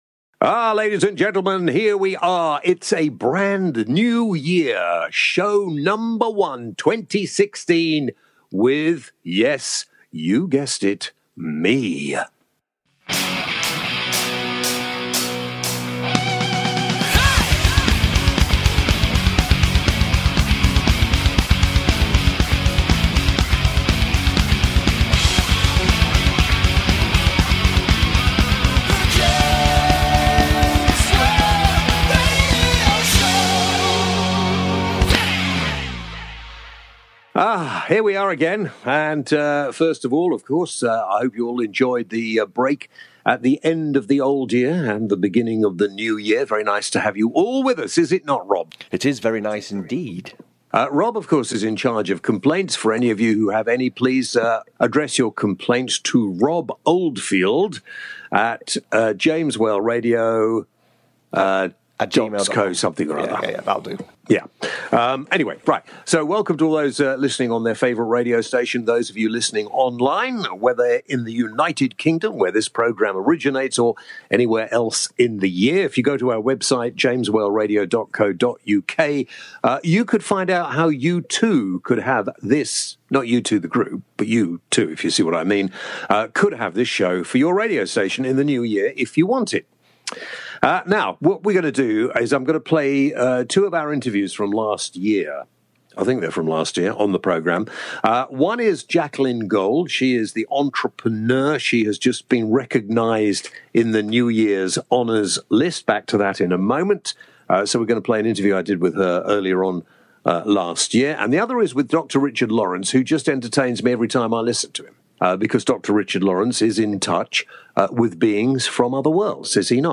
This week: An new year message from the Great Whale, Gift talk, and more. Plus a replay of some old interviews